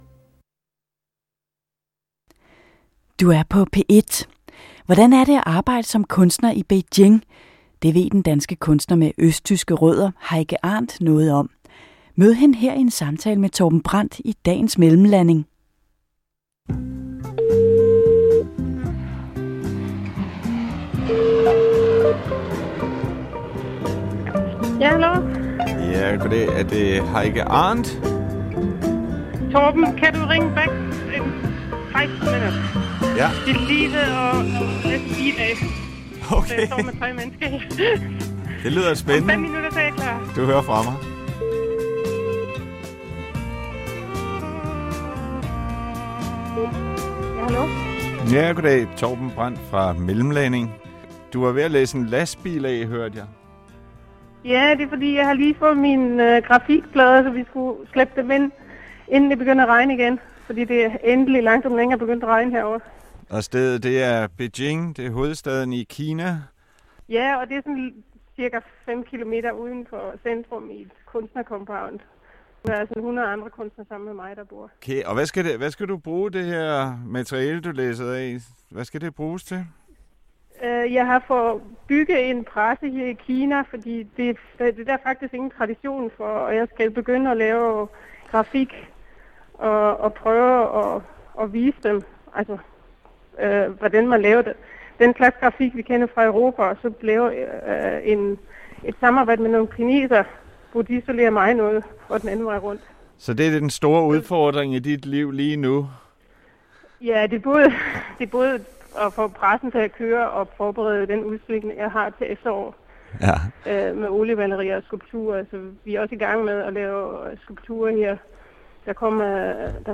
udsendelse mellemlanding optaged tidligere mens hun arbejdede i sit studio i Beijing